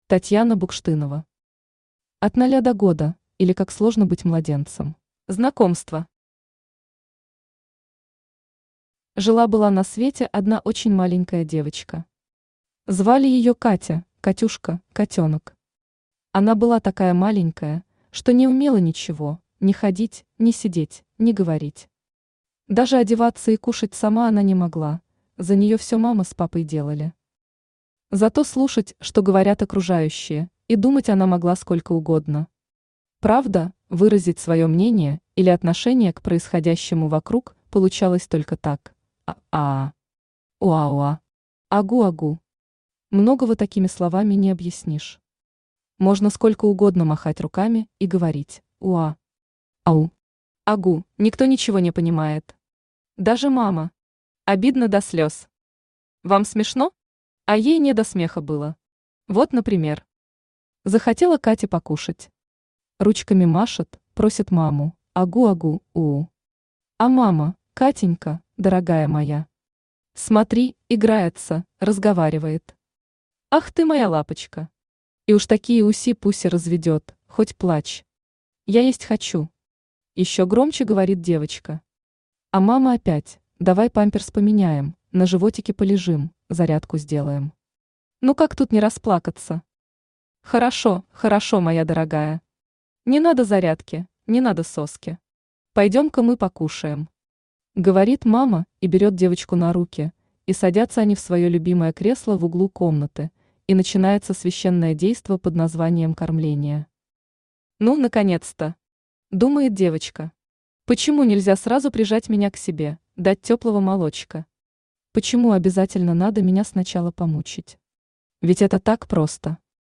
Аудиокнига От ноля до года, или Как сложно быть младенцем | Библиотека аудиокниг
Aудиокнига От ноля до года, или Как сложно быть младенцем Автор Татьяна Анатольевна Букштынова Читает аудиокнигу Авточтец ЛитРес.